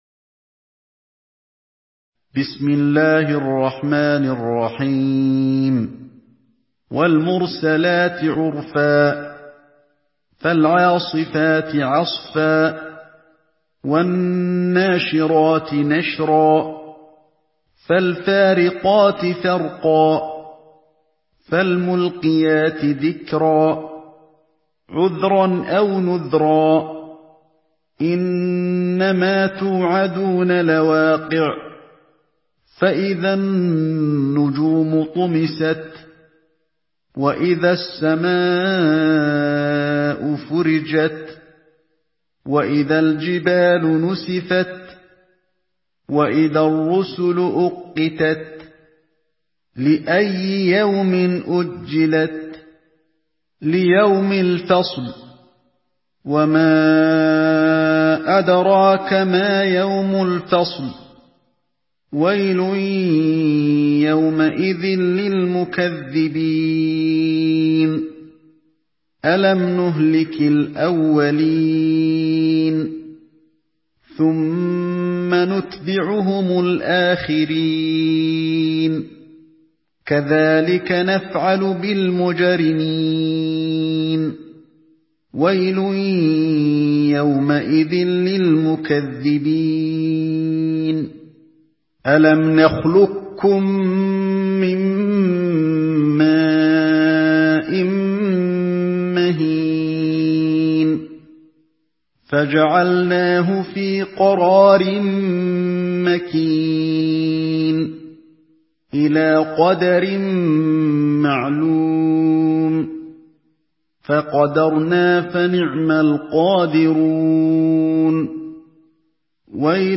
Surah المرسلات MP3 by علي الحذيفي in حفص عن عاصم narration.
مرتل